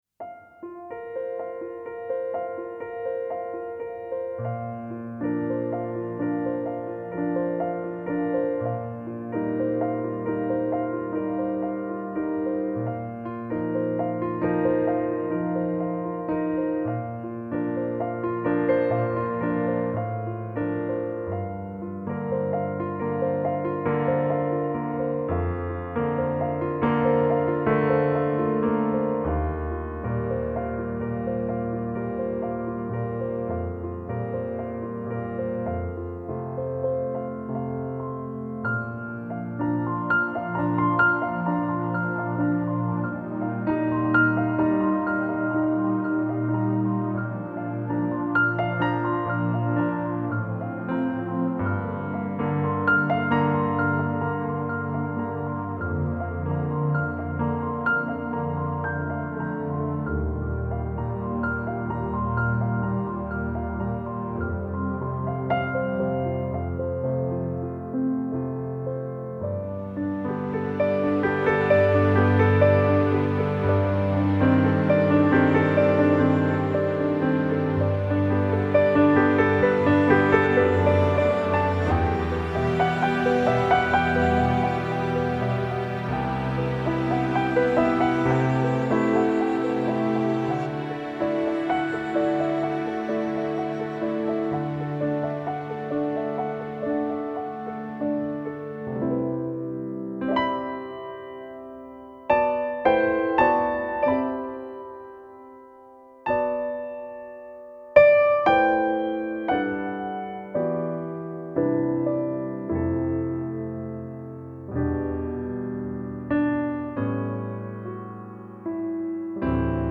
心灵钢琴
．音乐类别：新世纪治疗系音乐
．演 奏 家：钢琴